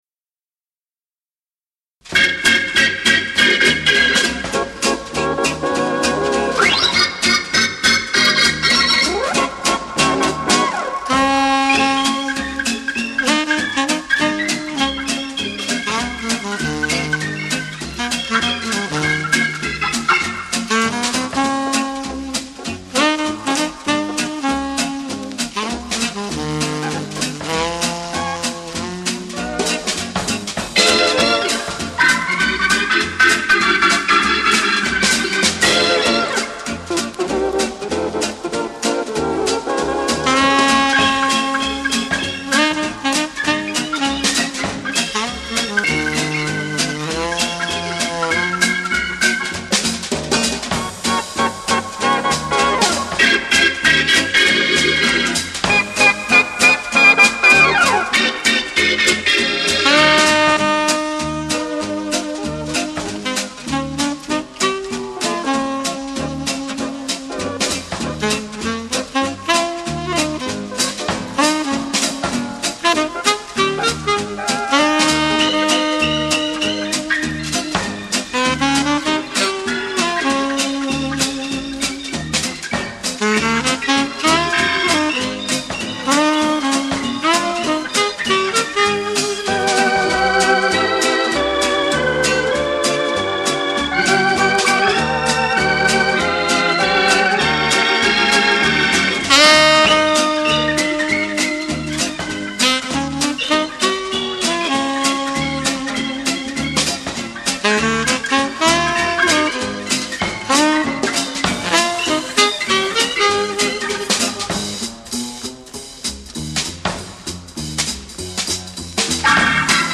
FOXTROT